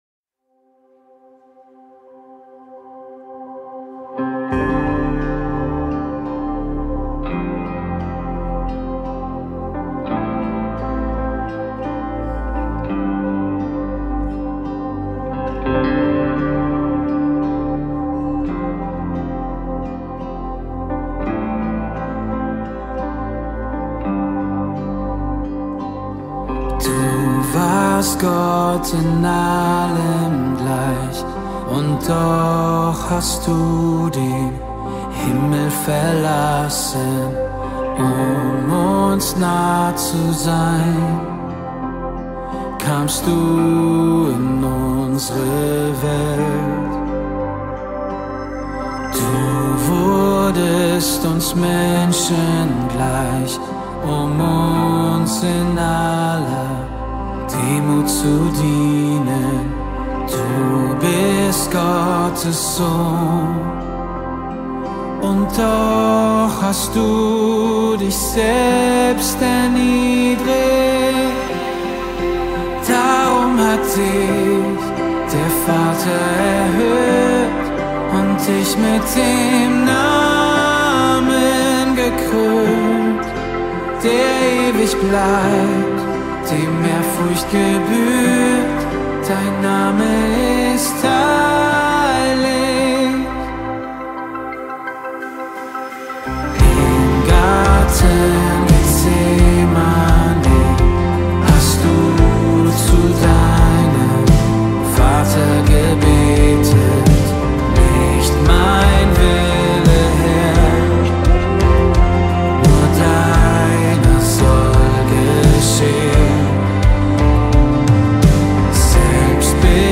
148 просмотров 118 прослушиваний 3 скачивания BPM: 85